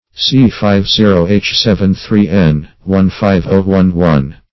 C50H73N15O11 - definition of C50H73N15O11 - synonyms, pronunciation, spelling from Free Dictionary
bradykinin \bra`dy*ki"nin\ n.